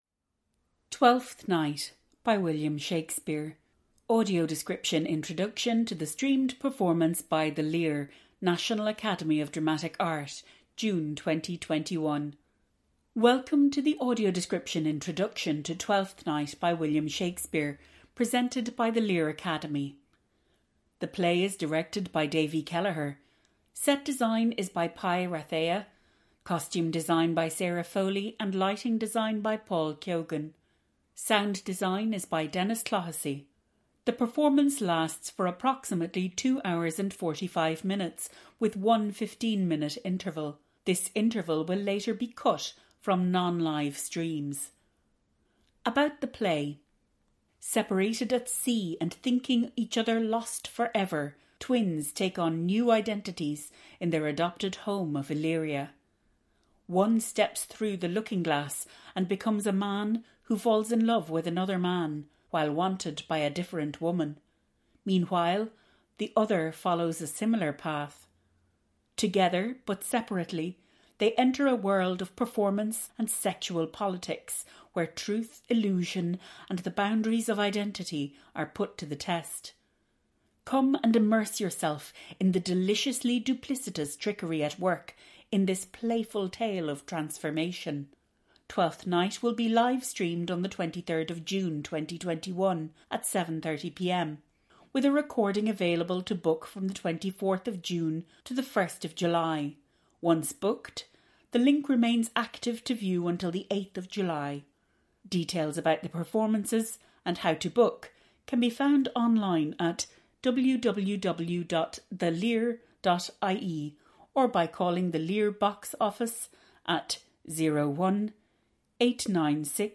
You can also listen to an audio described introduction here .
Twelfth-Night_AD-Introduction-1.mp3